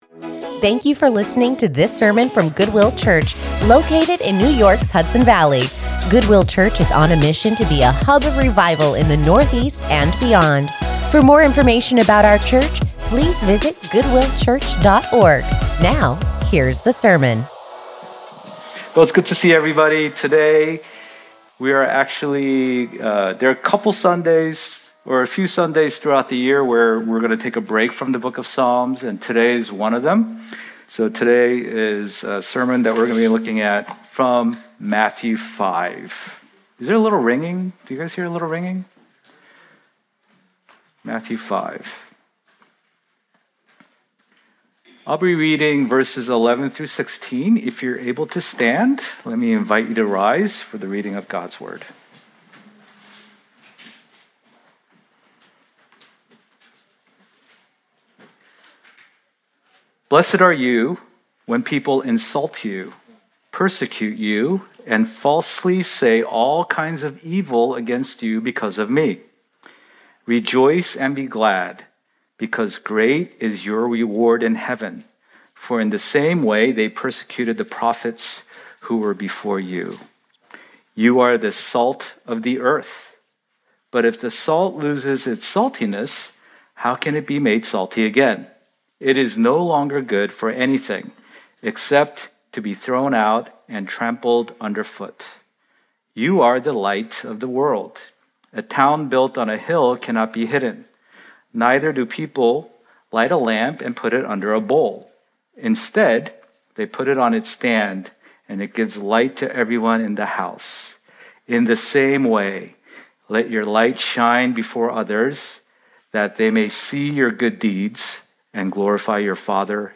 Join us in the study of God's Word as we take a break between sermon series